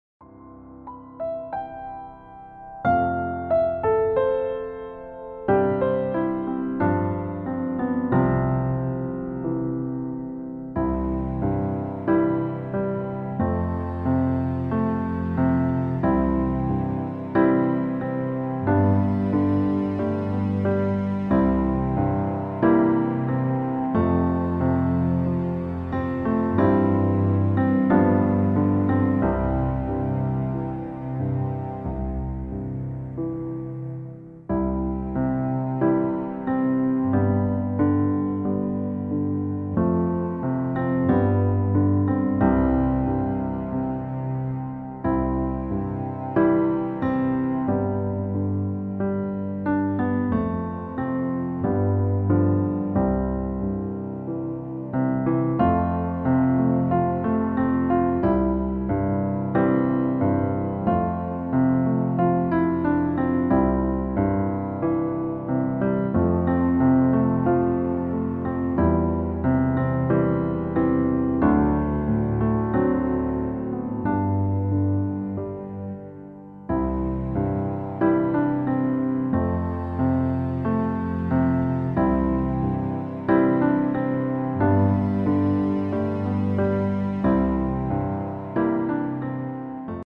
Klavierversion
• Tonart: C Dur, D Dur (weitere auf Anfrage)
• Art: Klavier Streicher Version
• Das Instrumental beinhaltet NICHT die Leadstimme
Klavier / Streicher